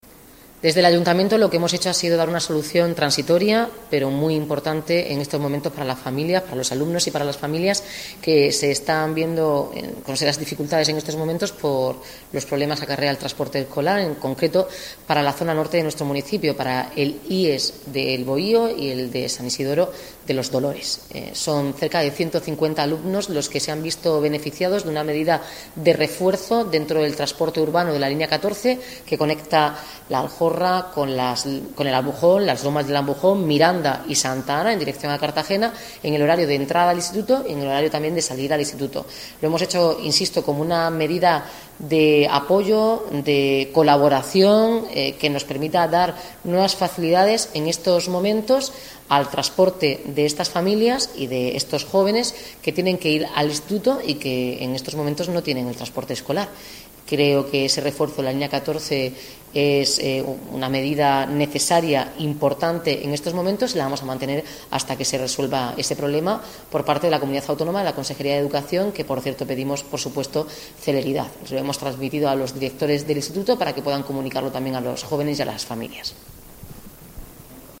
Enlace a Declaraciones de la alcaldesa, Noelia Arroyo, sobre el refuerzo de la línea 14 para los escolares